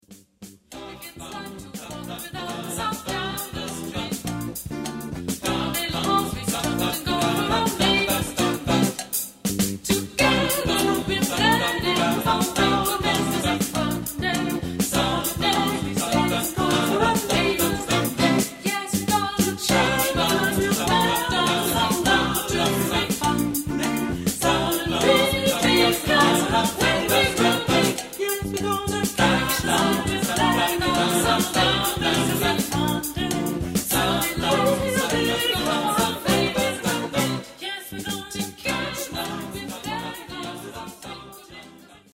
Genre-Style-Forme : Canon ; Calypso ; Ostinato ; Profane
Type de choeur : FTBB  (4 voix mixtes )
Instruments : Bongo (2)
Tonalité : do majeur